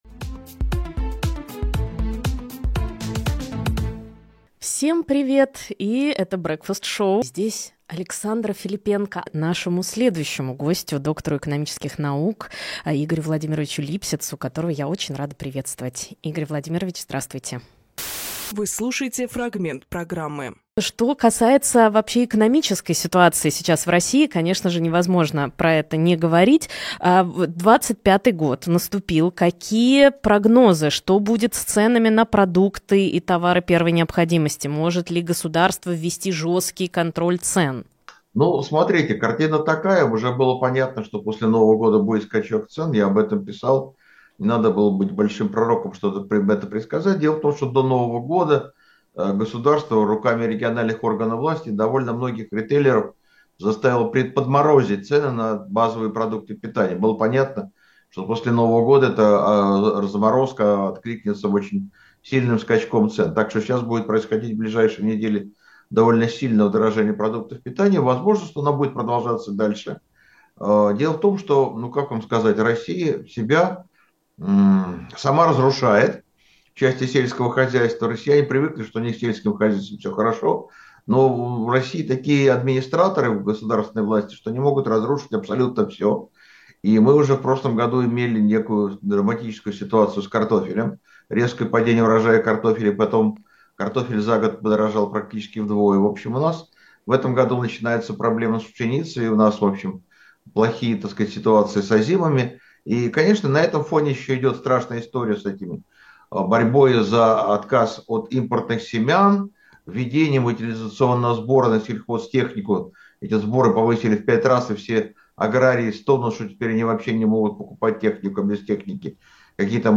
Фрагмент эфира от 12.01